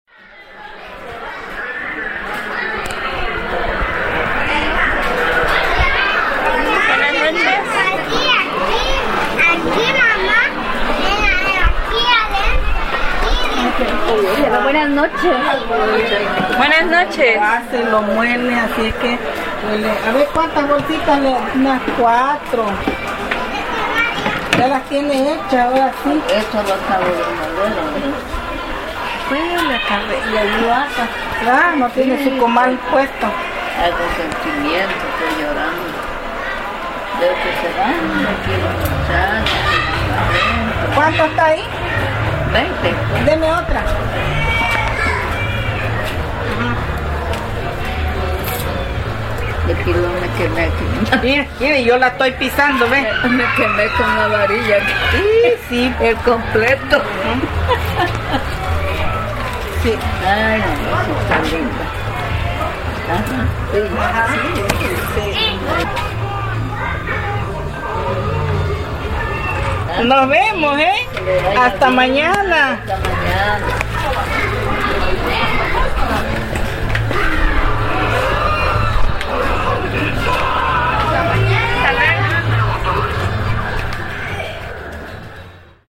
El tiempo se ha detenido en ese pequeño espacio cuyo sonido se mezcla con el bullicio de los juegos electrónicos.
Lugar: Azoyú, Guerrero; Mexico.
Equipo: Grabadora Sony ICD-UX80 Stereo